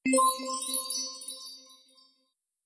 validation_sounds